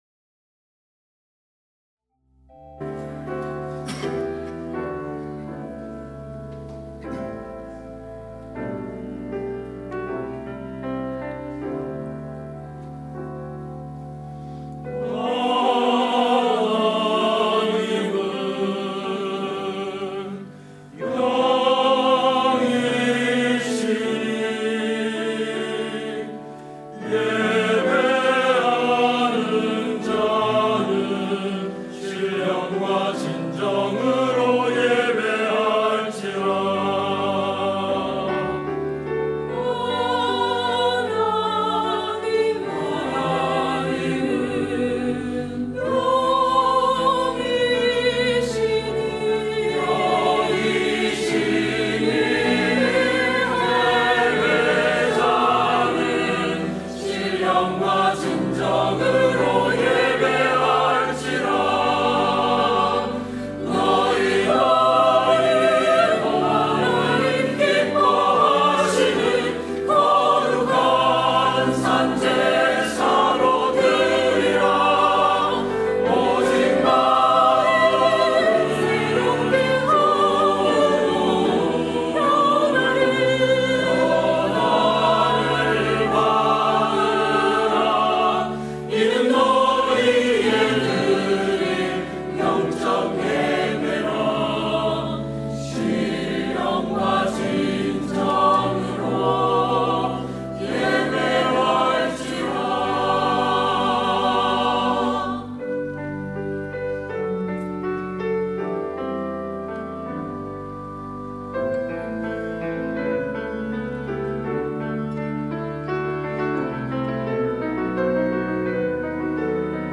예배